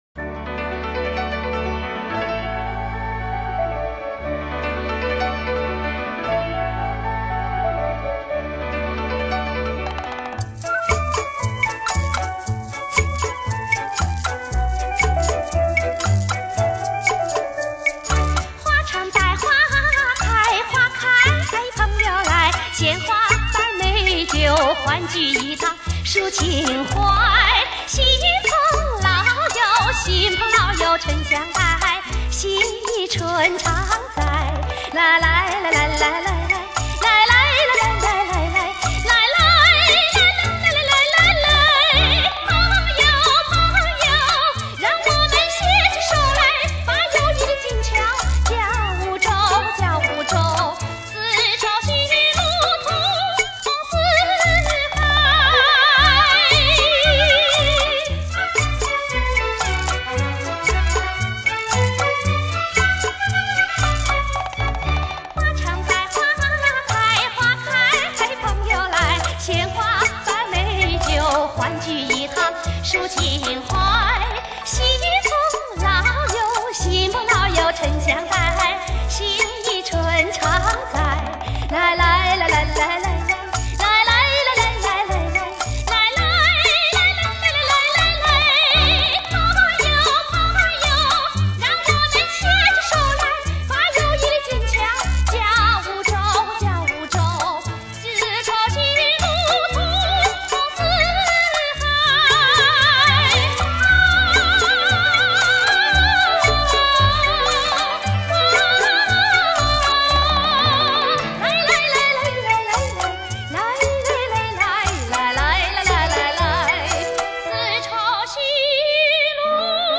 大大提高了音效品质，复原并提升了纯洁、真实、完美的效果，特别适合时下HIFI器材欣赏与感受。